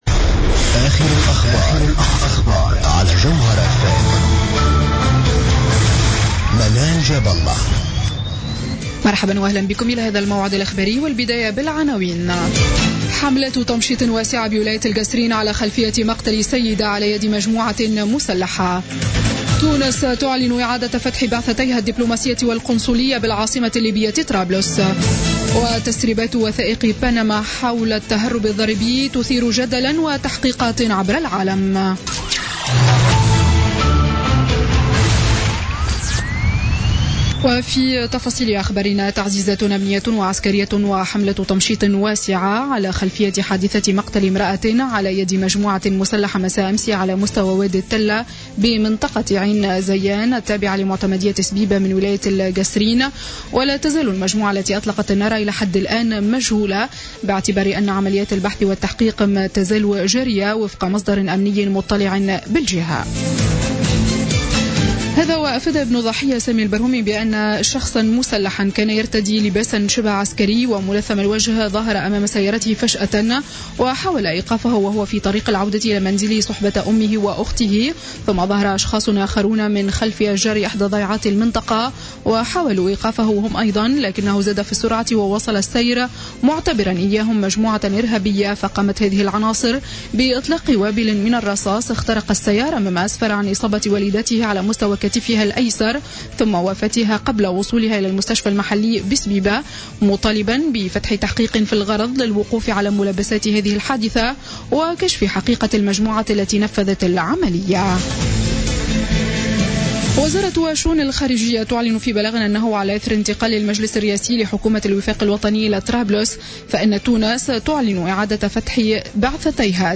نشرة أخبار السابعة مساء ليوم الاثنين 4 أفريل 2016